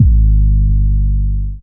DDW6 808 2.wav